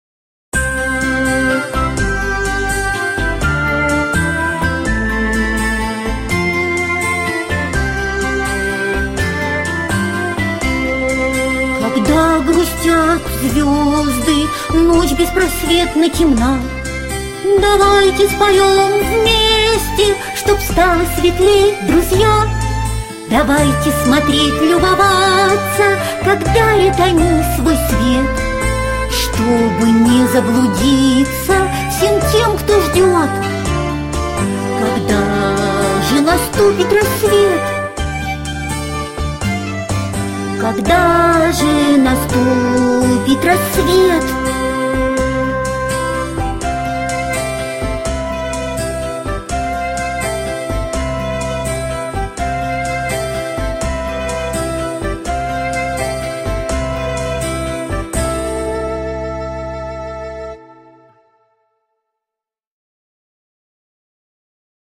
Музыкальная вариация на тему колыбельной